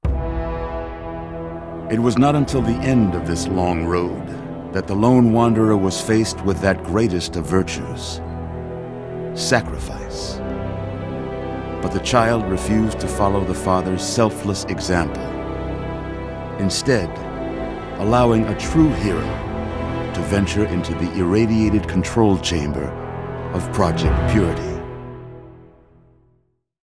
Category:Fallout 3 endgame narrations Du kannst diese Datei nicht überschreiben.